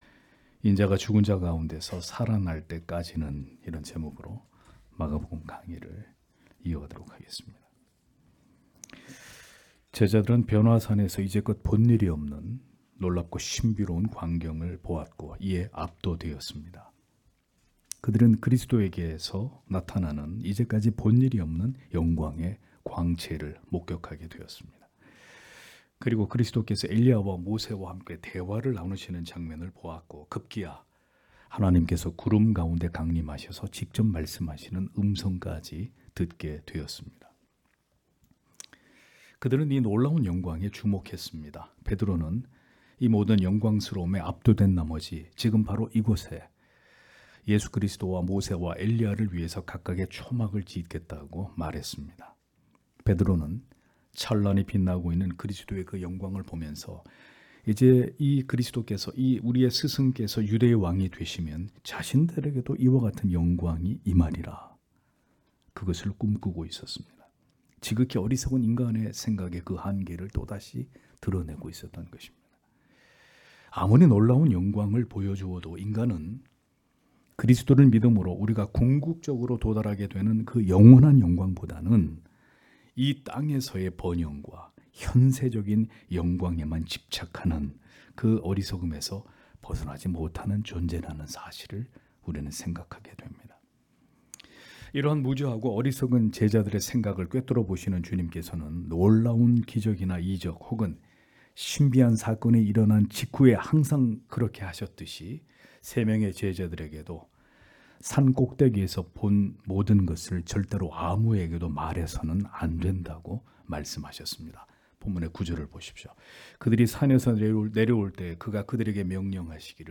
주일오전예배 - [마가복음 강해 35] 인자가 죽은 자 가운데서 살아날 때 까지는 (막 9장 9-13절)